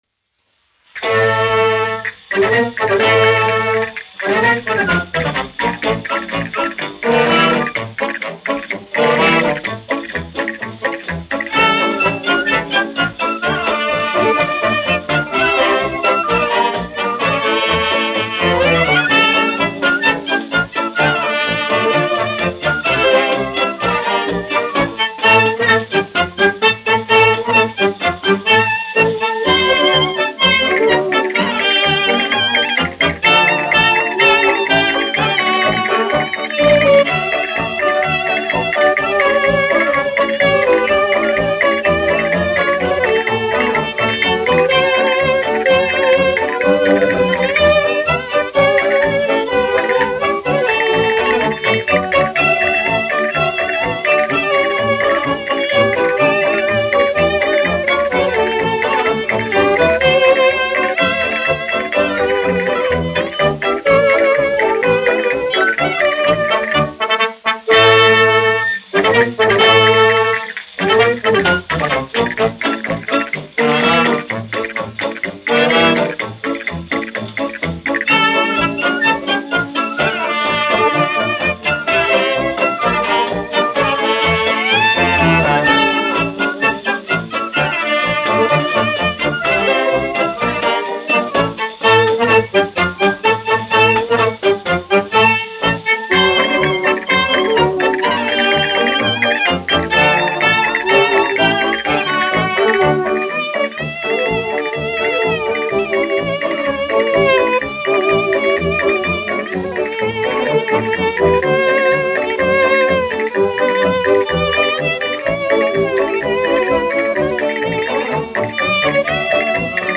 1 skpl. : analogs, 78 apgr/min, mono ; 25 cm
Populārā instrumentālā mūzika
Sarīkojumu dejas
Skaņuplate
Latvijas vēsturiskie šellaka skaņuplašu ieraksti (Kolekcija)